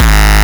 razor kick.wav